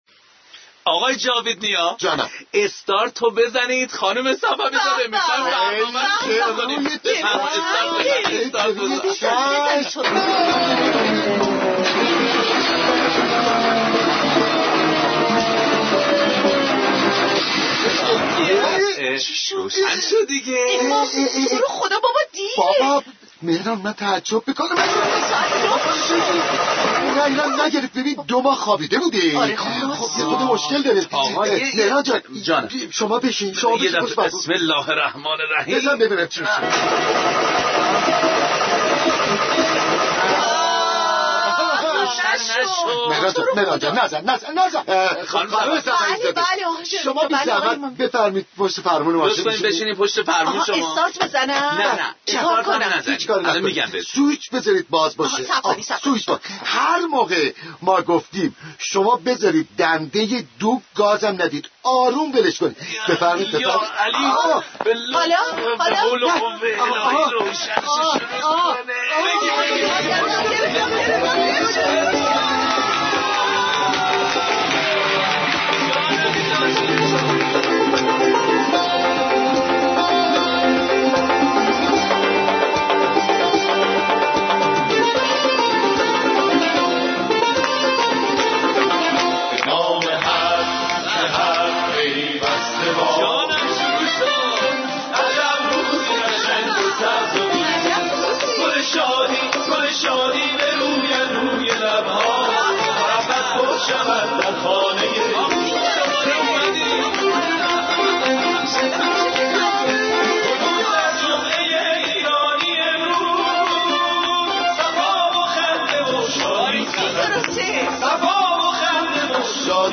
برنامه طنز رادیو ایران